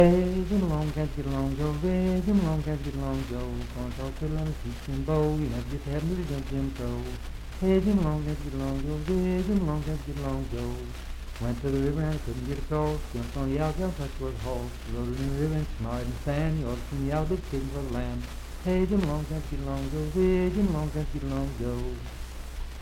Unaccompanied vocal music
Dance, Game, and Party Songs, Minstrel, Blackface, and African-American Songs
Voice (sung)
Marlinton (W. Va.), Pocahontas County (W. Va.)